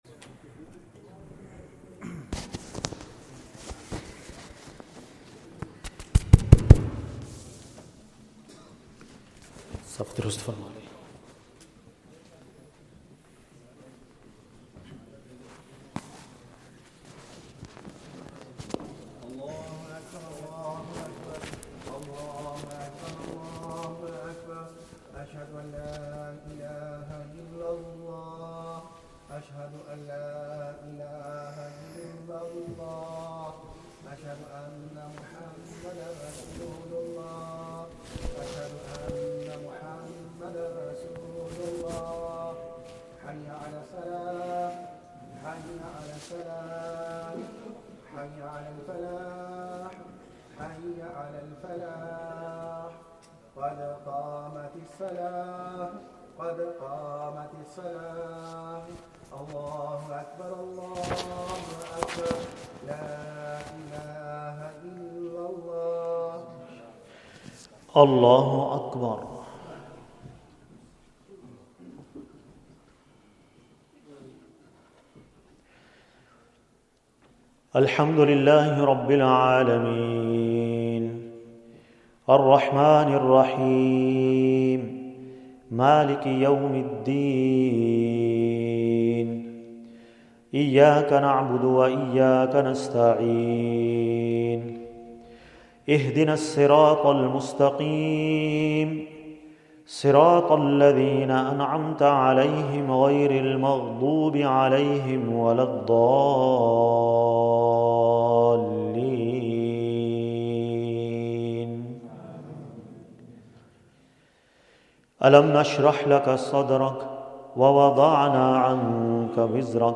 Taraweeh